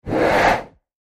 CloseHandheldTorch PE361702
Close Handheld Torch Whoosh, X6